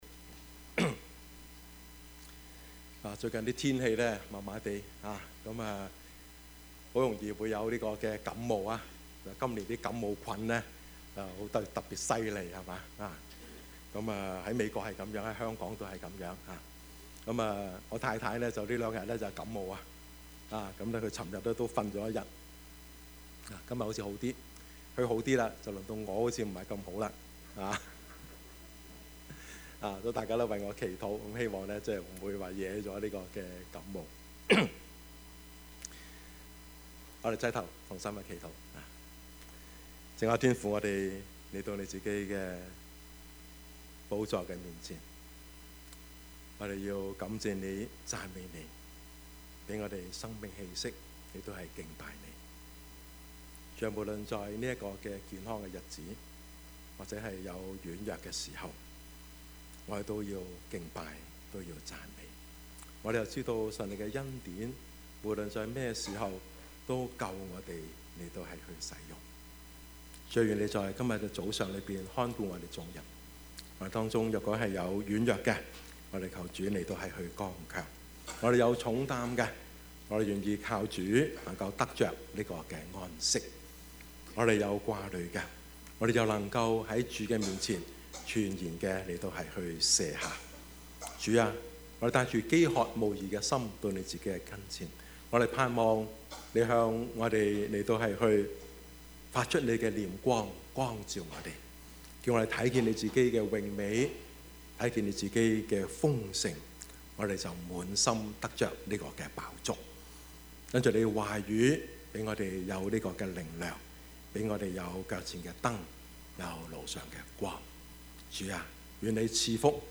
Service Type: 主日崇拜
Topics: 主日證道 « 寬恕之道 跟從主耶穌 »